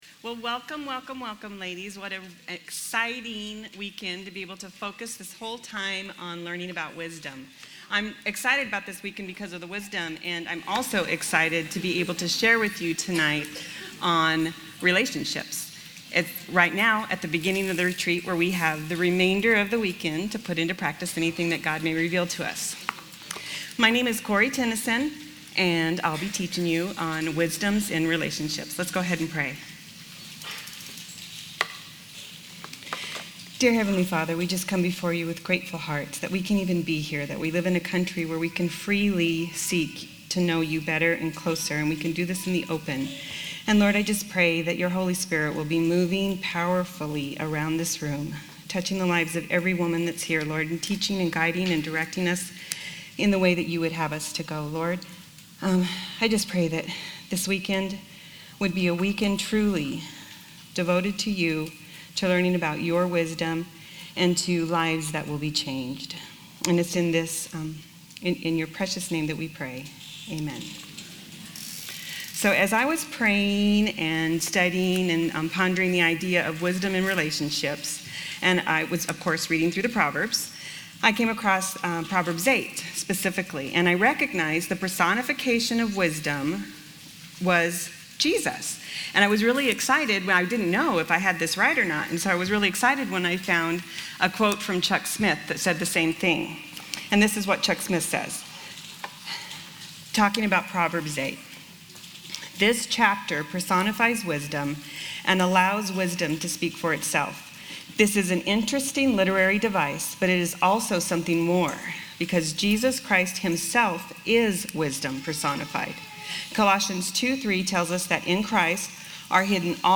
at the 2015 Women's Retreat: Pearl's of Wisdom